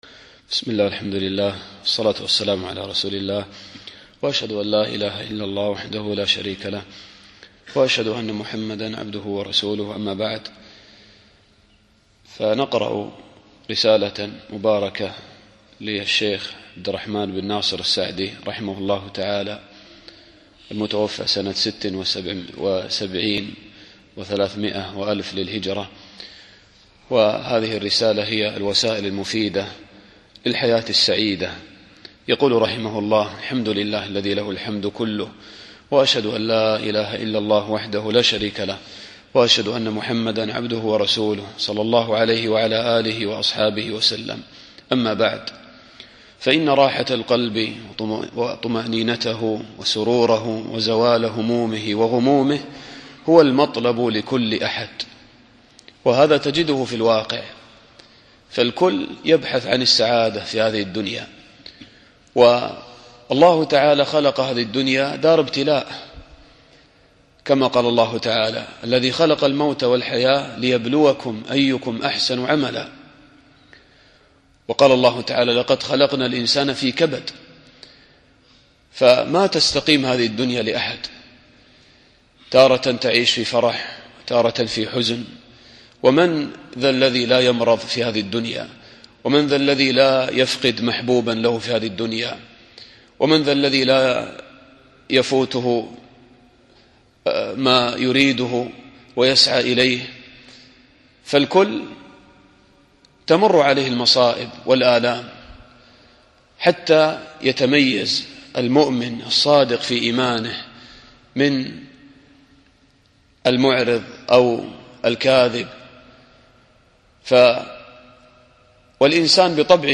الدرس الأول